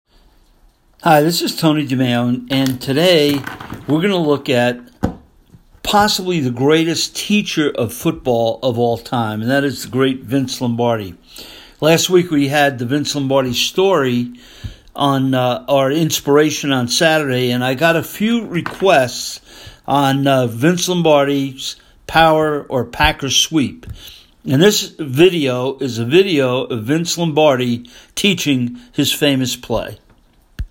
Vince Lombardi Teaching The Green Bay Sweep
Rather than me trying to explain the Lombardi, sweep, I’ll leave it to the master, Vince Lombardi himself to explain it after this short introduction.